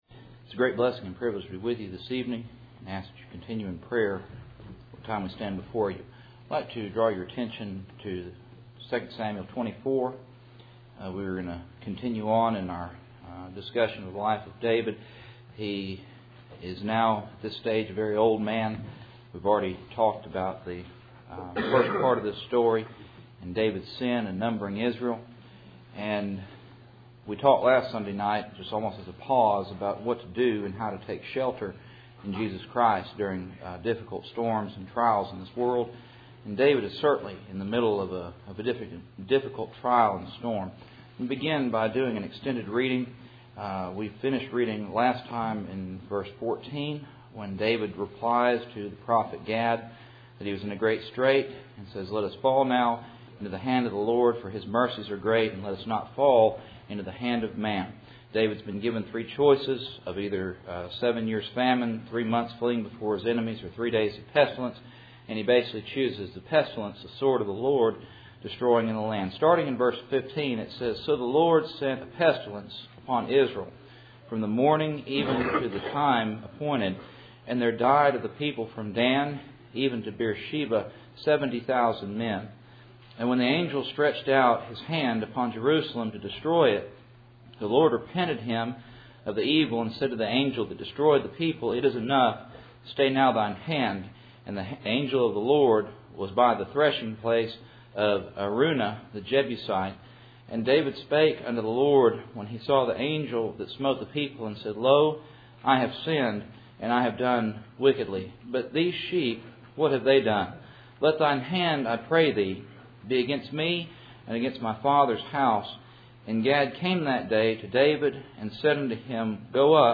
Life of David Service Type: Cool Springs PBC Sunday Evening %todo_render% « Judgment